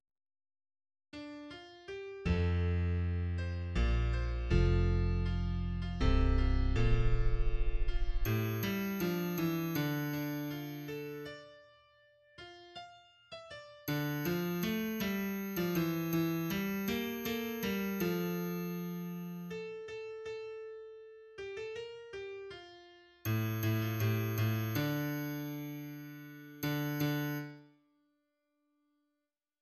3rd verse